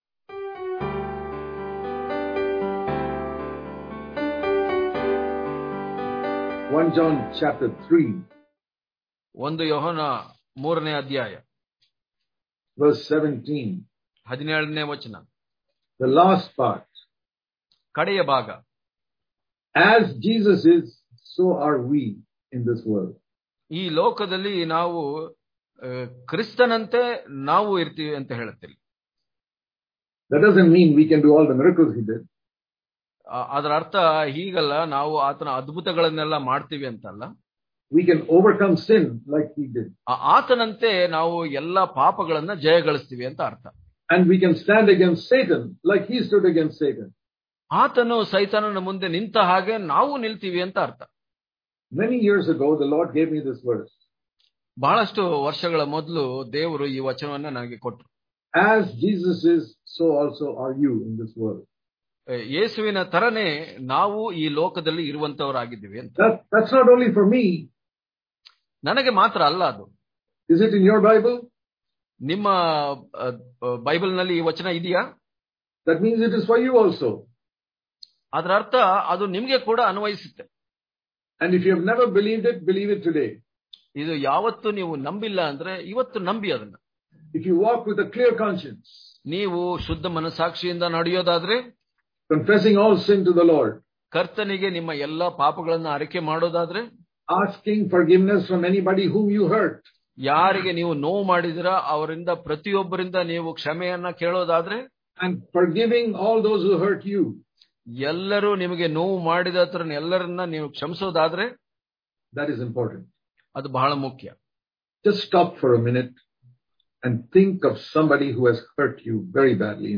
August 22 | Kannada Daily Devotion | The Meaning Of Loving Jesus Wholeheartedly Daily Devotions